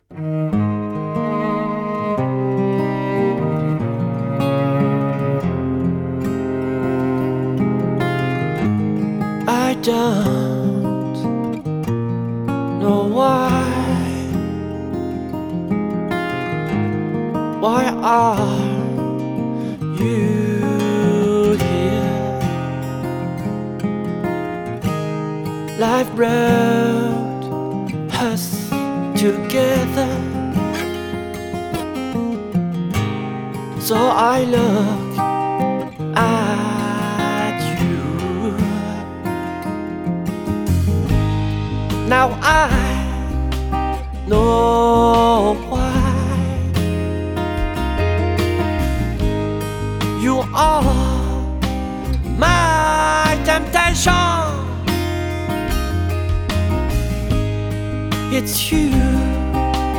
Entre folk intimiste, accents soul et éclats rock
Guitariste passionné